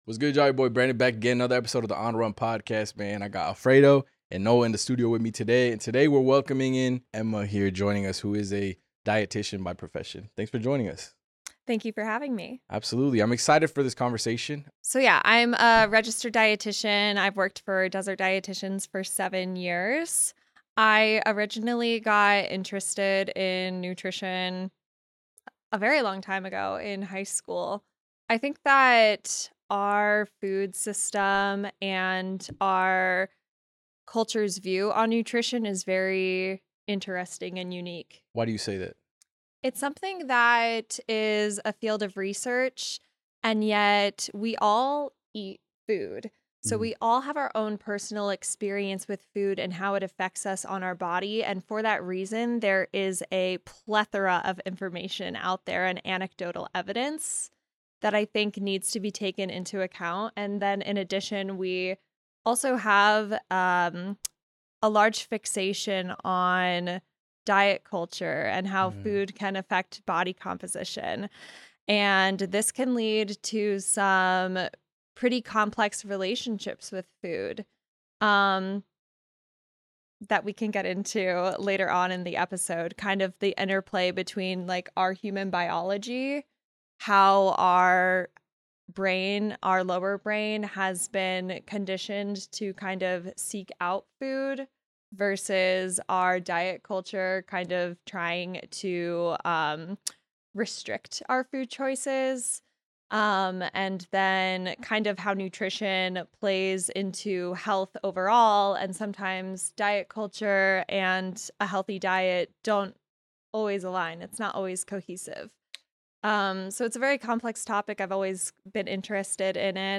Hit play for an honest, eye-opening, and empowering conversation that might just shift your whole mindset!